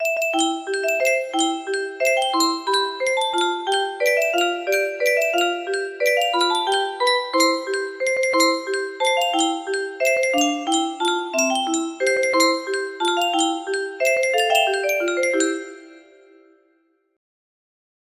Unknown Artist - Untitled music box melody
It looks like this melody can be played offline on a 30 note paper strip music box!